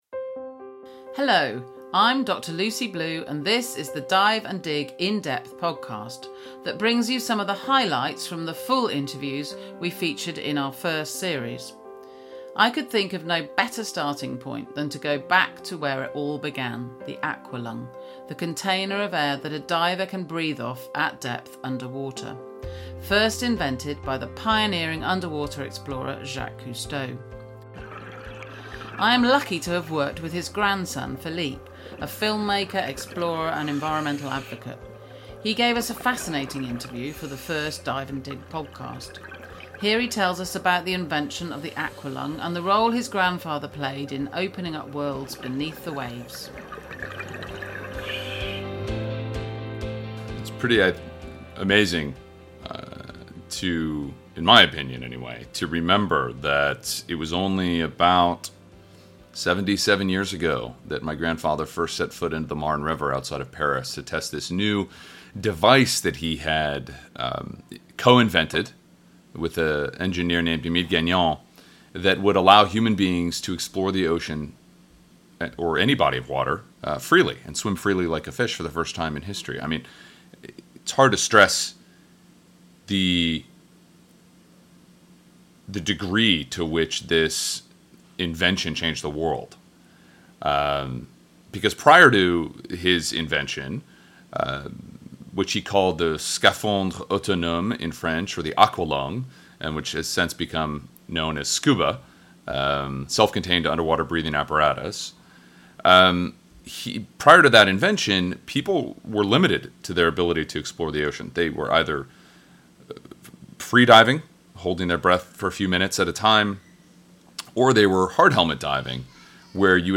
Our Dive & Dig In Depth series contains some of the full interviews from series one of Dive & Dig. Starting with Philippe Cousteau, explorer and environmental advocate.